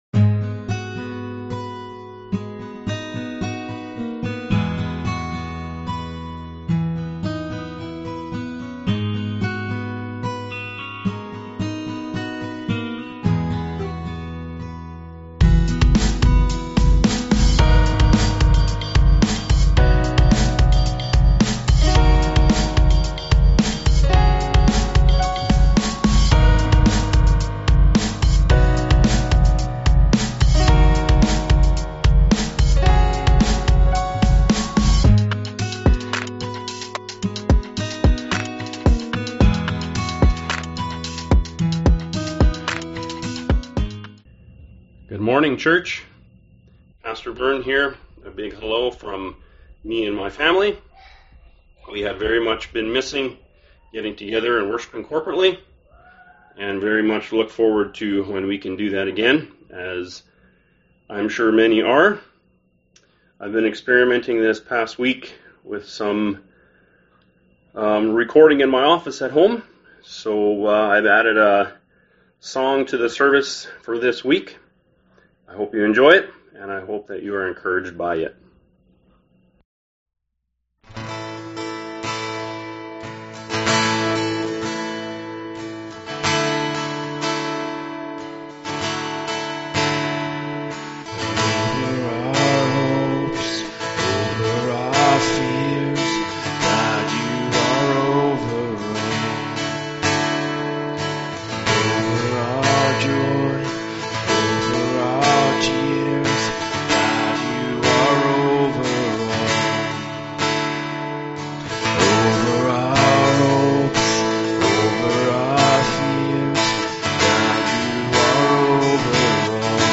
May 24th Service